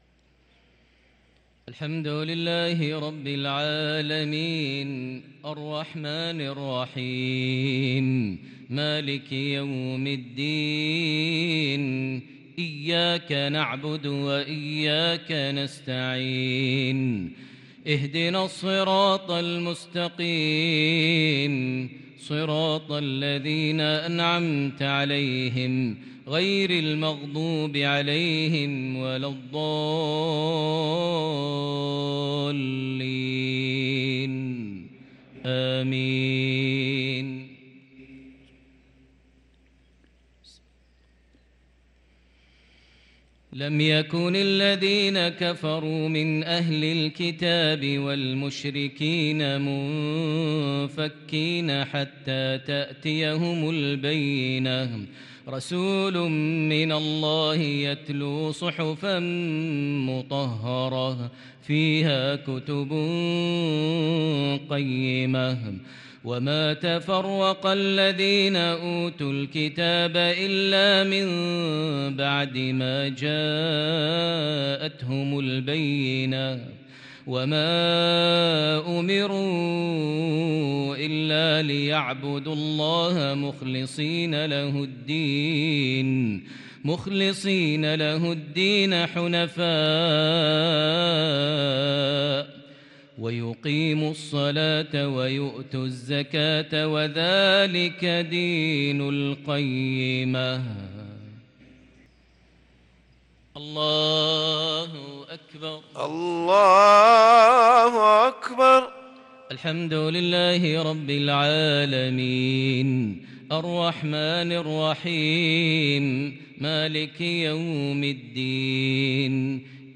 صلاة المغرب للقارئ ماهر المعيقلي 22 ربيع الأول 1444 هـ
تِلَاوَات الْحَرَمَيْن .